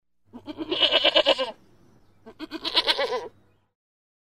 Звуки козы
2. Коза произносит ме-е-е